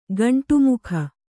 ♪ gaṇṭu mukha